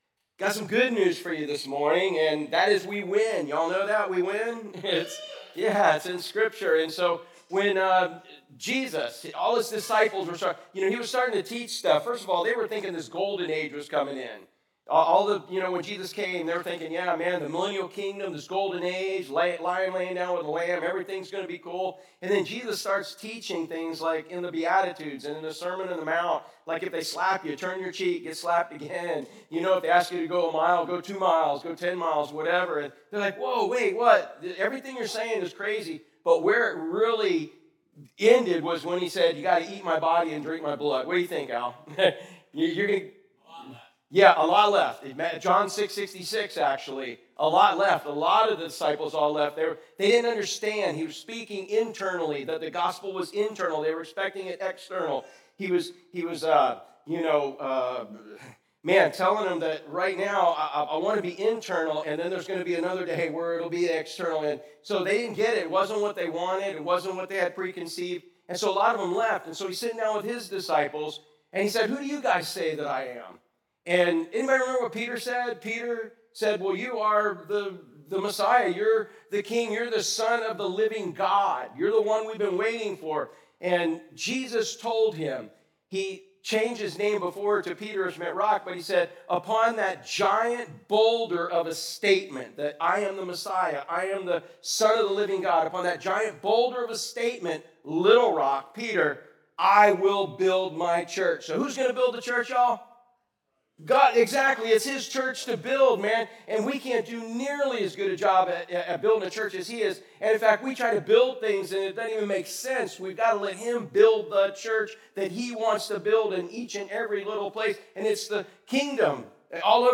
Sermons | Driftwood Church at the Beach